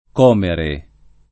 comere [ k 0 mere ] v.; como [ k 0 mo ] — latinismo poet. per «abbellire»: con tutta quella Arte che tanto il parlar orna e come [ kon t 2 tta k U% lla # rte ke tt # nto il parlar 1 rna e kk 0 me ] (Ariosto)